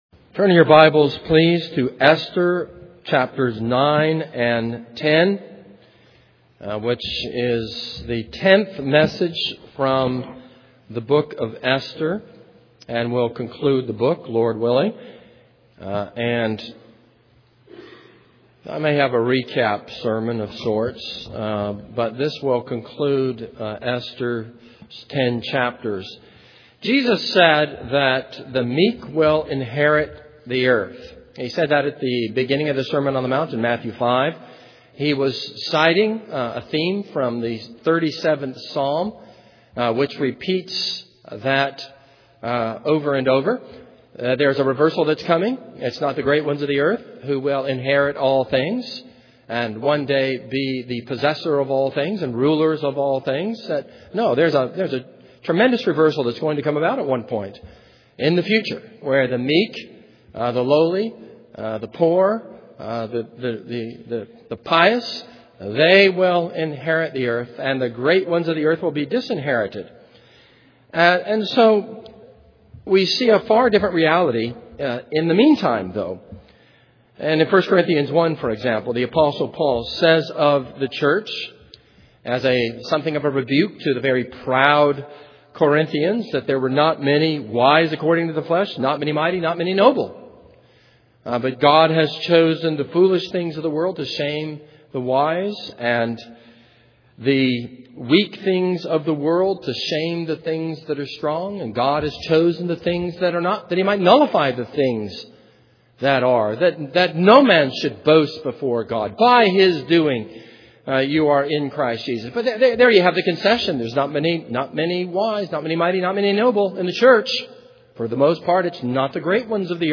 This is a sermon on Esther 9-10.